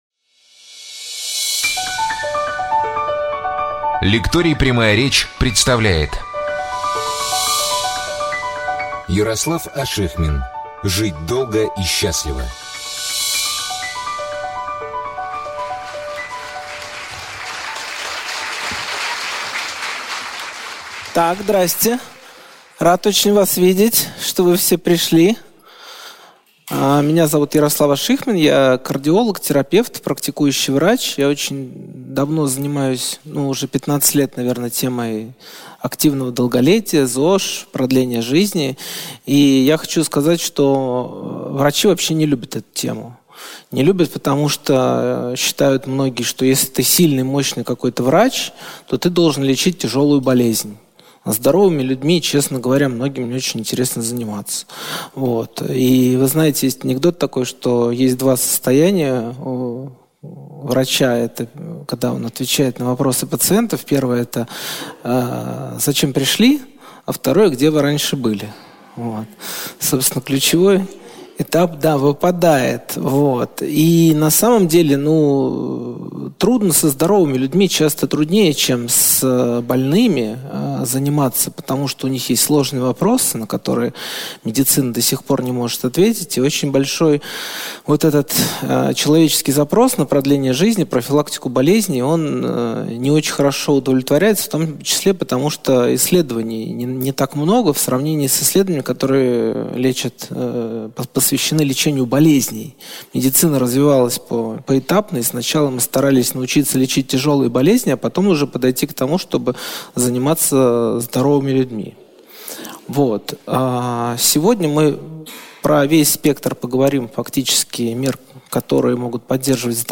Жанр: Биохакинг, Здоровье, Здоровье без лекарств, Курс лекций, Лекторий Прямая речь, Лекции